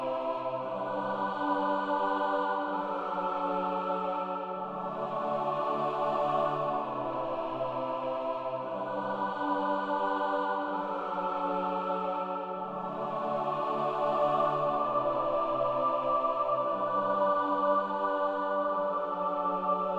Musique multipistes.
Pistes : 9 (dont des cœurs)